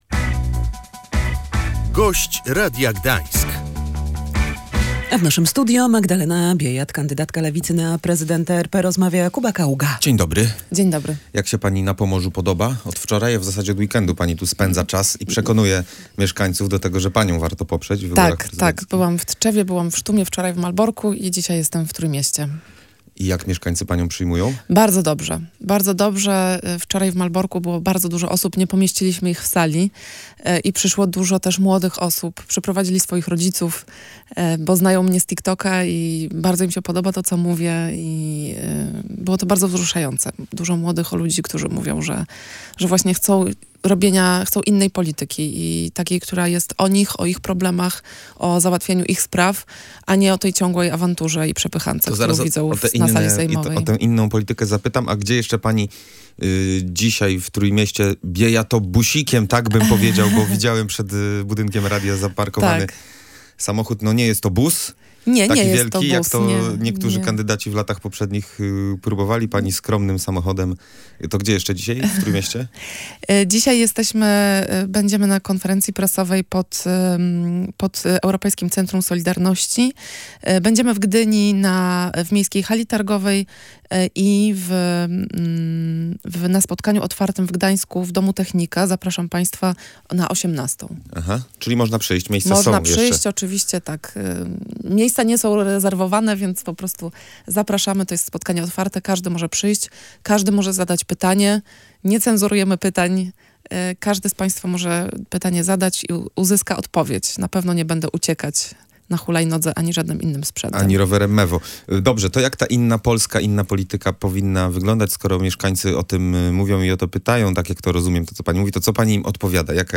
Kandydatka na prezydenta Polski z Nowej Lewicy mówiła w Radiu Gdańsk, że – mimo swojej przynależności partyjnej – liczą się dla niej potrzeby Polaków, a nie spory polityczne.